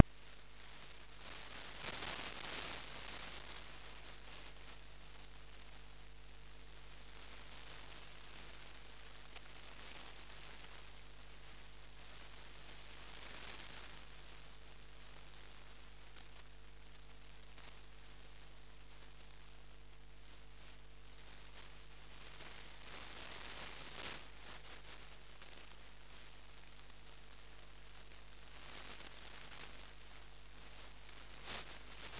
принято мной на частоте около 7000 кгц примерно в начале июня
Сигнал с огромной шириной полосы
очень сильным уровнем и странным звучанием